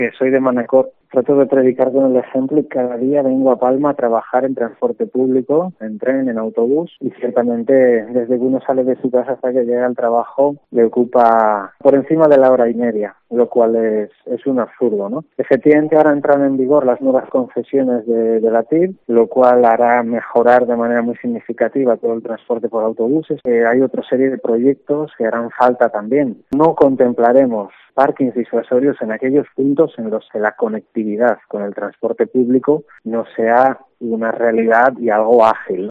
Conseller de Movilidad, Iván Sevillano.